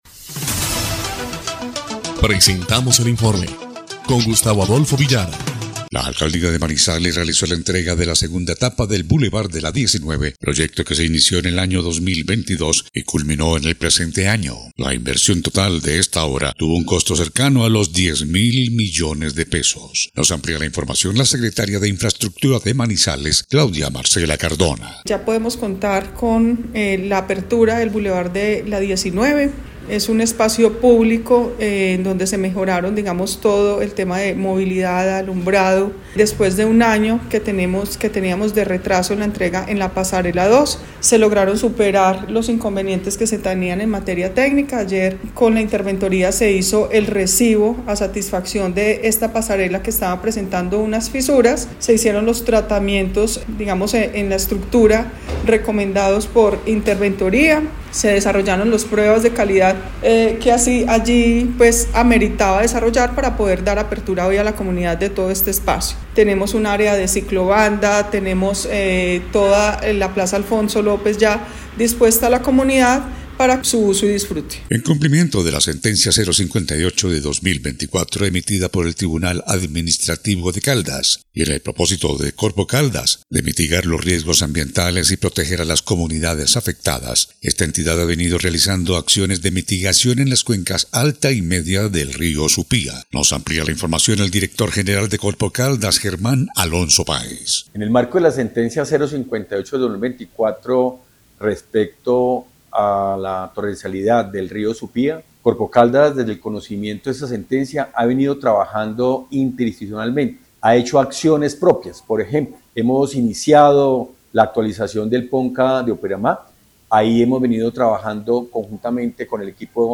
EL INFORME 3° Clip de Noticias del 21 de marzo de 2025